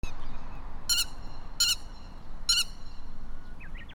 田んぼの中で鳴いている鳥 単音 奈良県松尾山
/ D｜動物 / D-05 ｜鳥